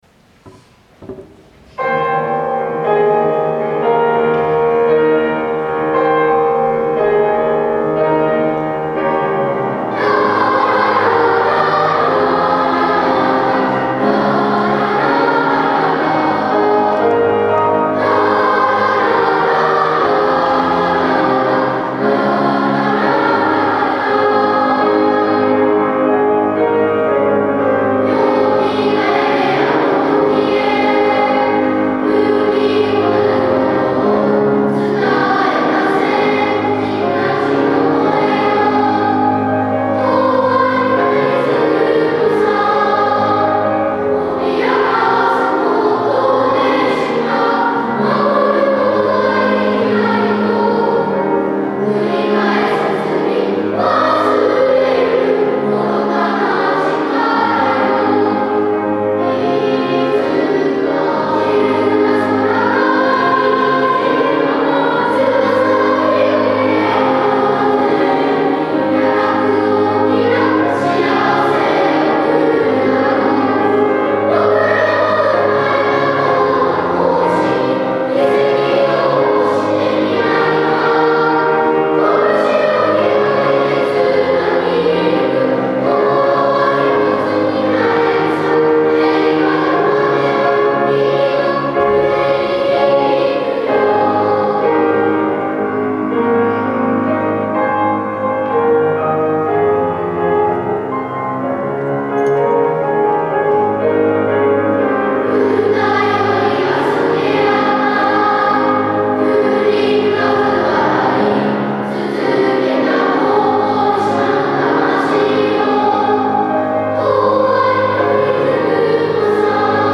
優しいハーモニーに思わず胸が熱くなりました。
３曲目は、「校歌」全校２部合唱。